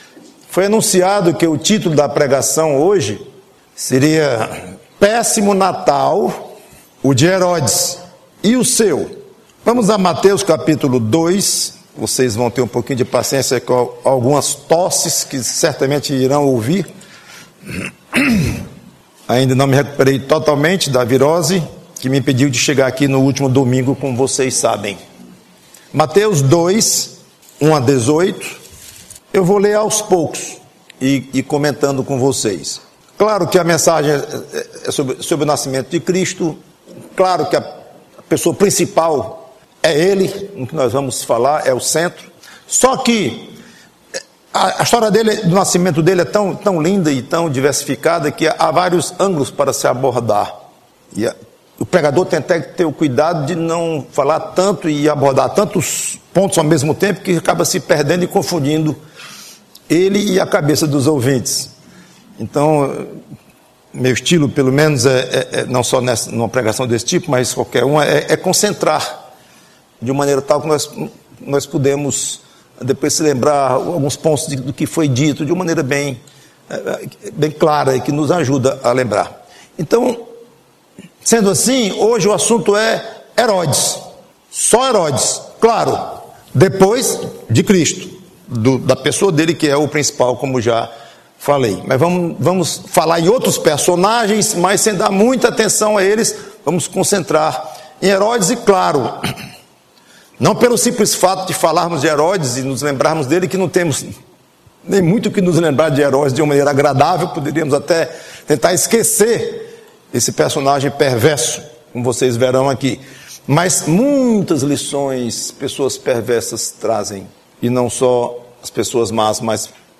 PREGAÇÃO NATAL: Péssimo Natal, o de Herodes.